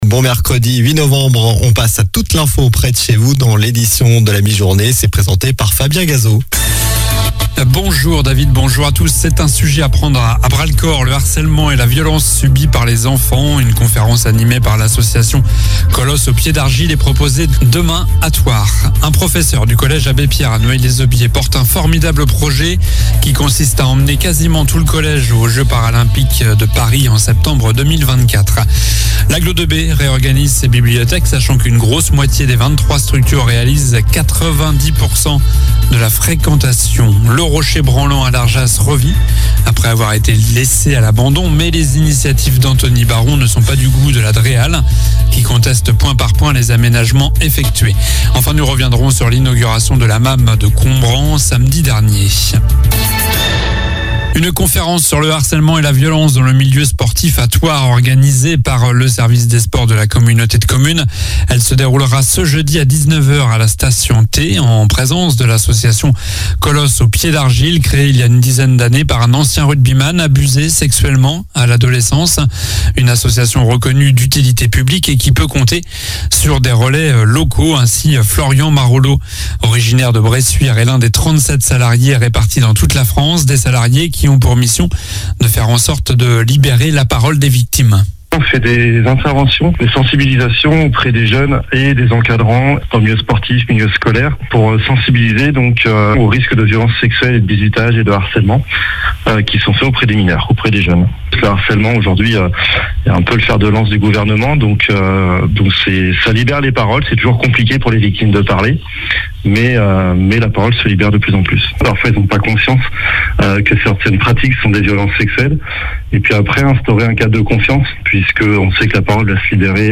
Journal du mercredi 08 novembre (midi)